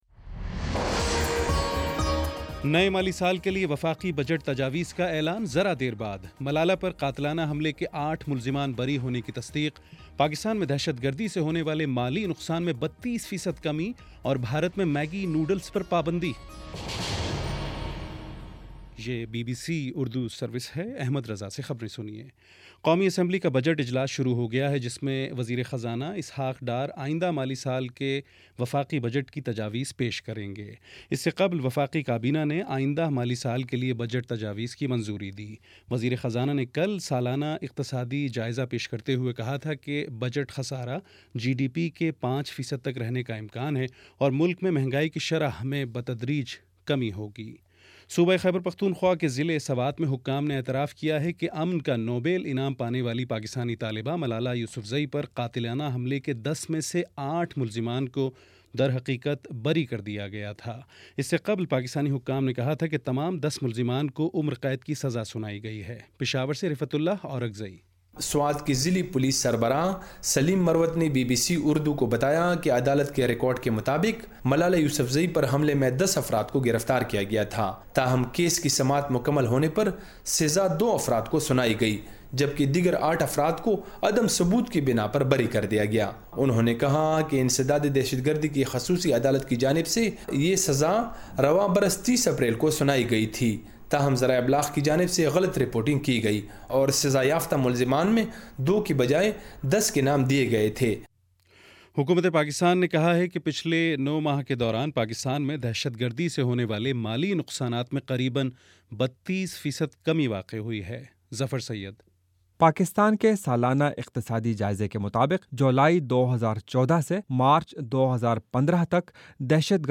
جون 5: شام پانچ بجے کا نیوز بُلیٹن